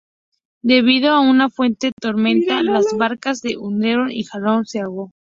tor‧men‧ta
/toɾˈmenta/